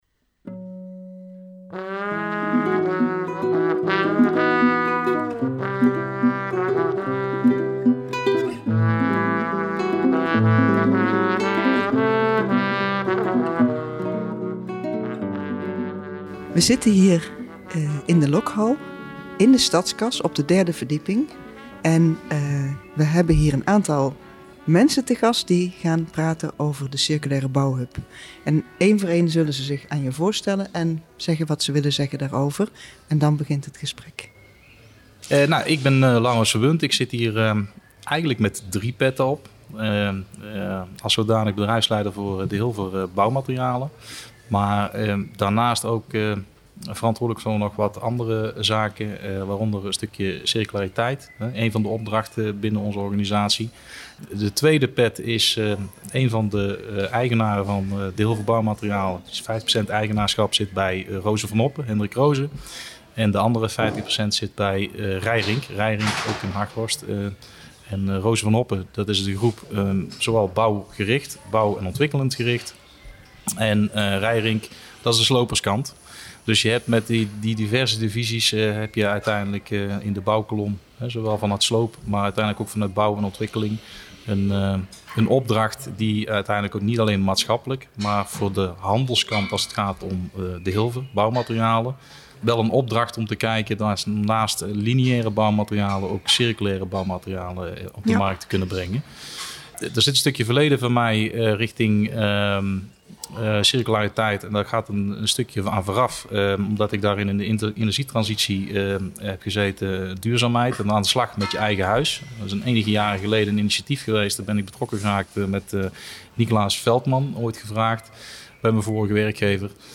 Ontdekspoor #9 - Circulaire Bouwhub - in gesprek over het initiatief